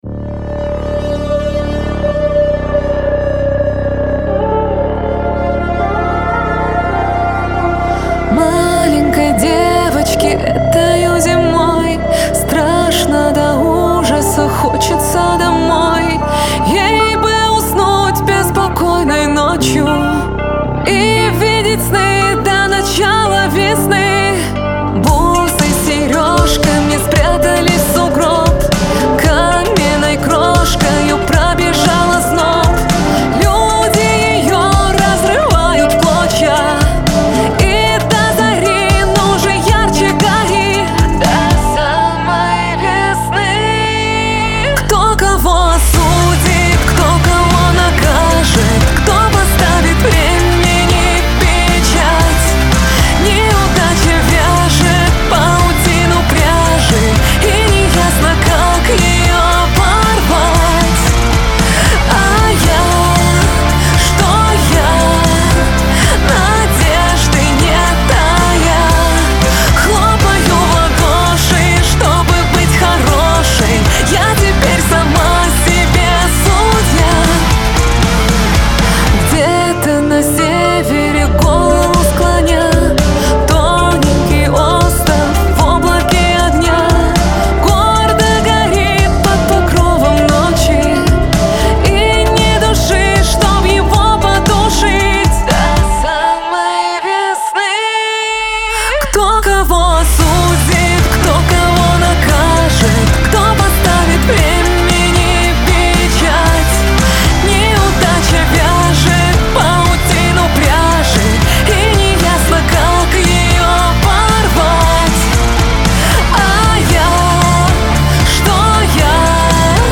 Категории: Русские песни, Альтернатива.